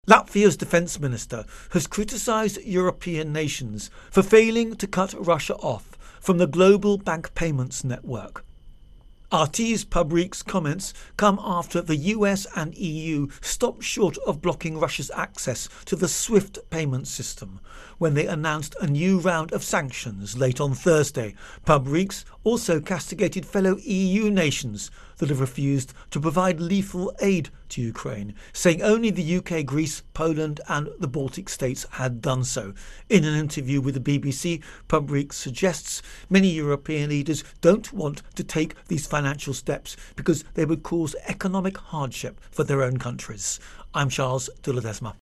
Ukraine-Invasion Latvia-Russia Intro and Voicer